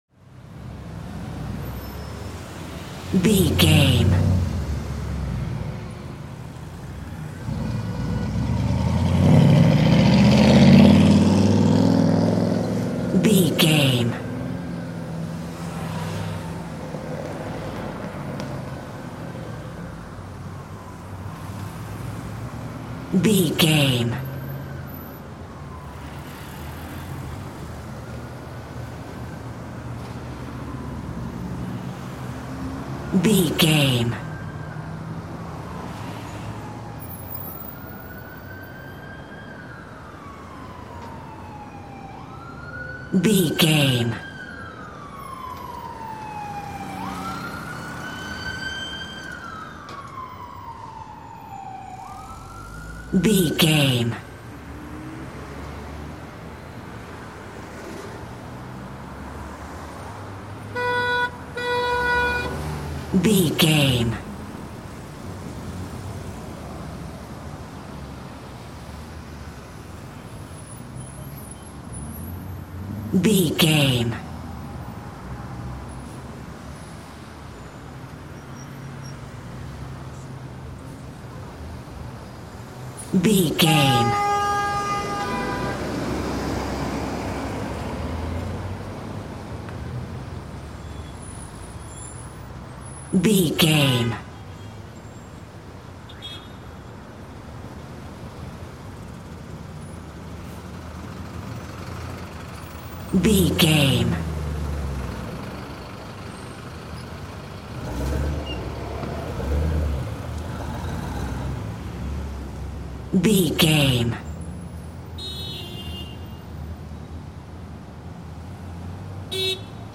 City street traffic
Sound Effects
urban
chaotic
ambience